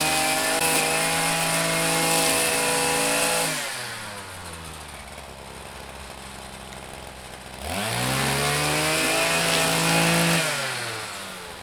• grass trimmer 5.wav
grass_trimmer_5_kYw.wav